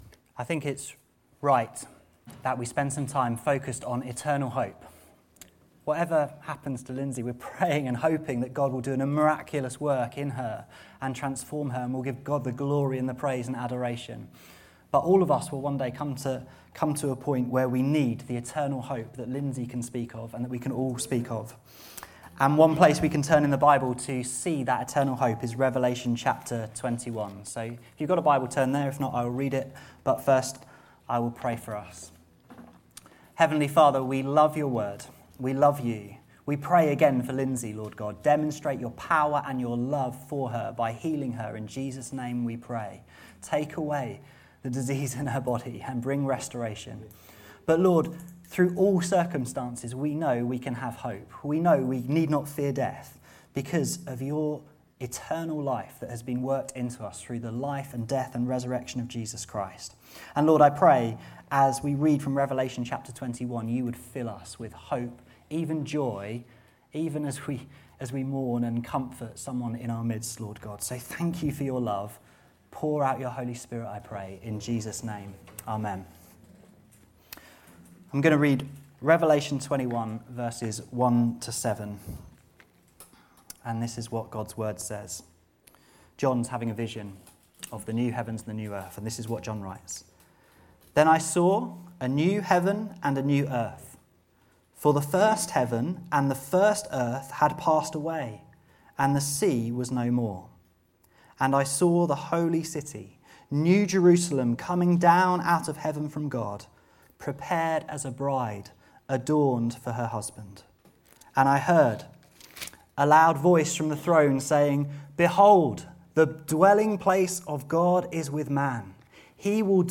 This sermon focuses on the glorious future that awaits all who believe and have placed their faith in Jesus Christ. The eternal hope believers share helps us persevere in challenging times and live joyfully knowing what awaits.